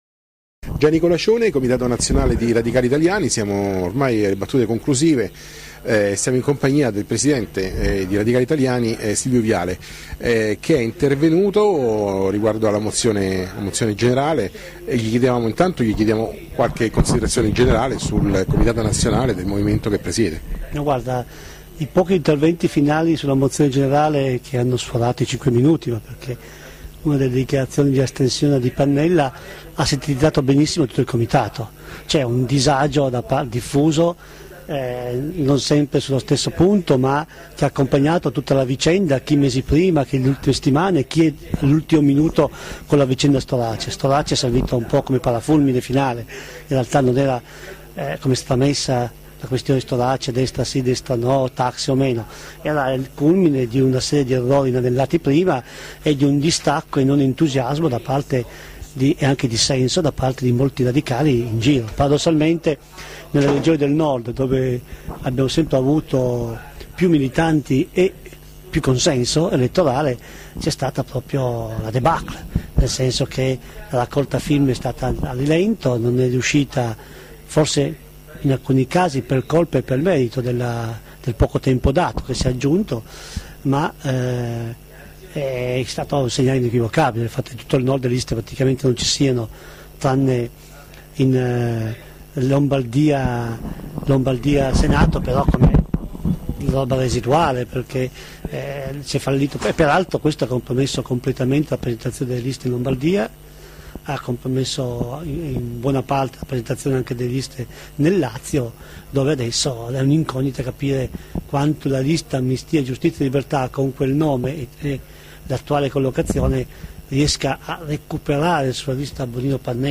Comitato Nazionale Radicali Italiani del 1-2-3 febbraio 2013, Roma presso la sede del Partito Radicale, terza giornata.
Intervista al Presidente di Radicali Italiani Silvio Viale.